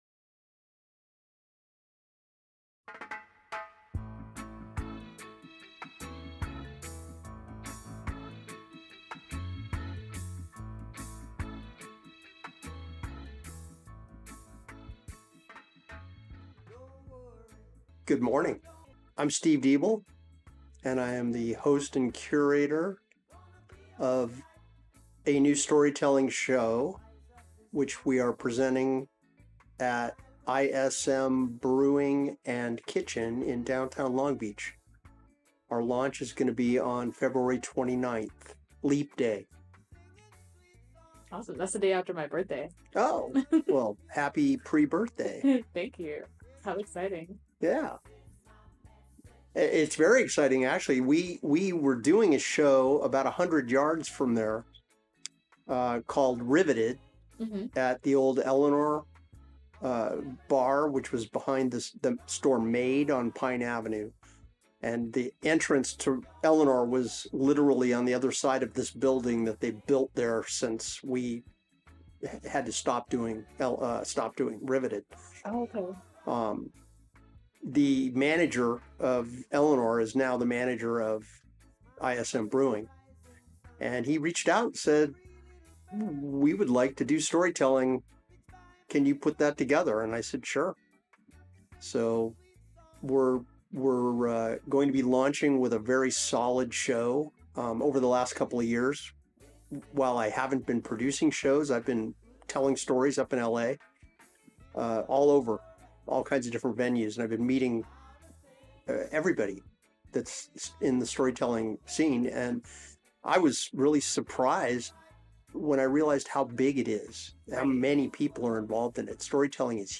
This segment aired live on CityHeART Radio at 11am Wednesday Feb 21, 2024 during the daily Voices from THE HUB.